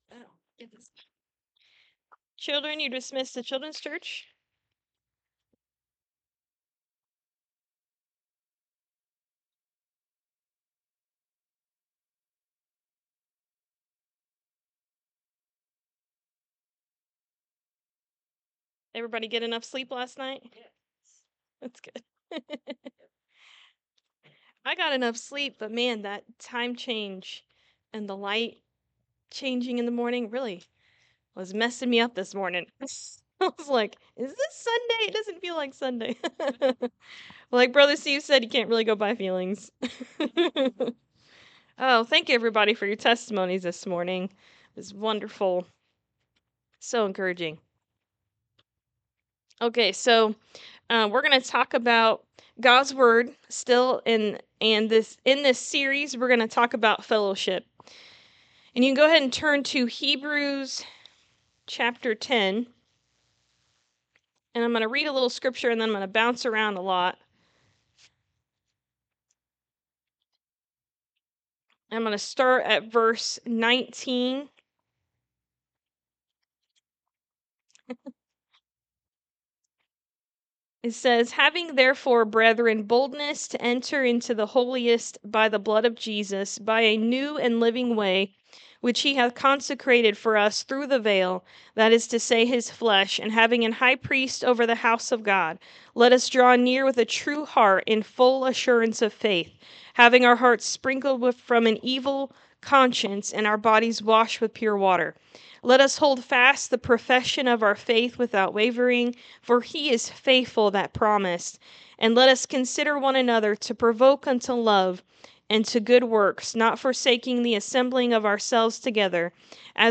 God’s Word Series Sermon 9 Fellowship
Hebrews 10:25 Service Type: Sunday Morning Service Have you ever thought about how the world has adopted Christian terms lately?